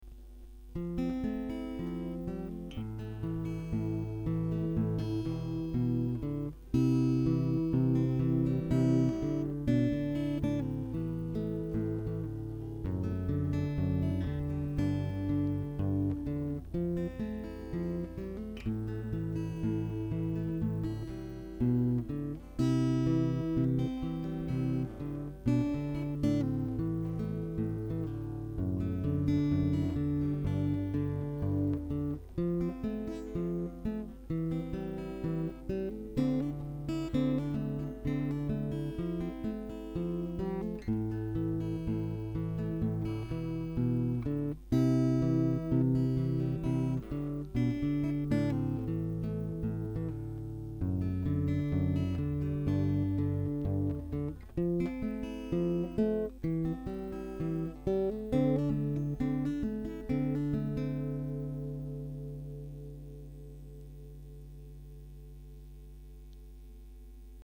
Alors voila deux trois samples (massacres) de ma petite gratte une Ibanez PF60Ce, rien à voir avec les canons du topic .
C'est enregistré avec la gratte branchée directementen jack et Audacity.